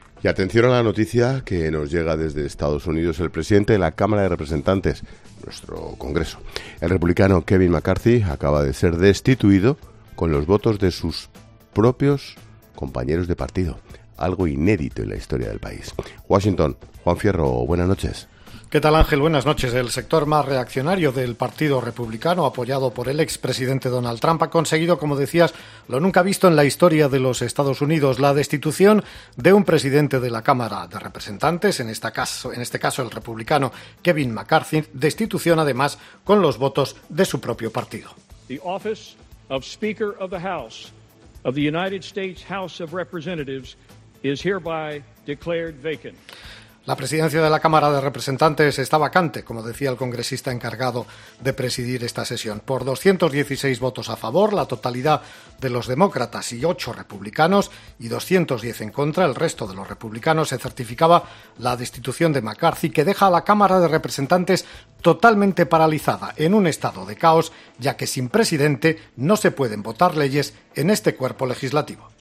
corresponsal en Estados Unidos